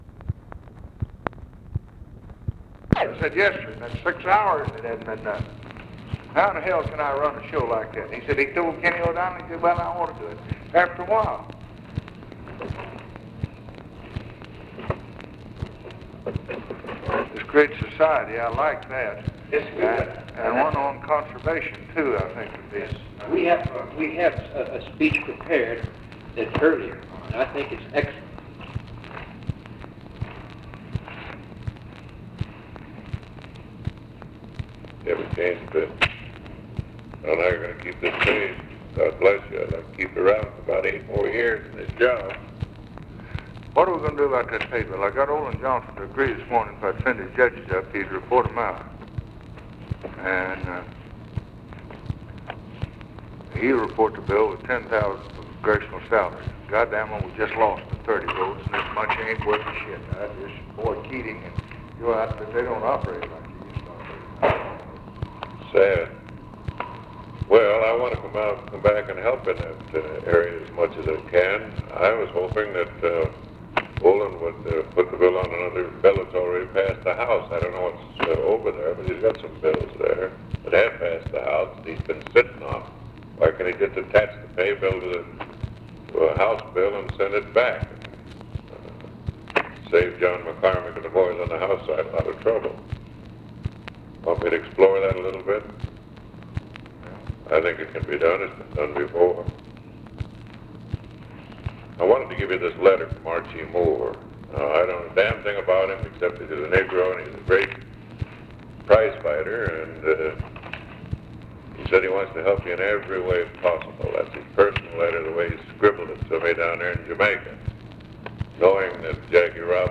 OFFICE CONVERSATION, April 14, 1964
Secret White House Tapes